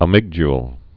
(ə-mĭgdyl)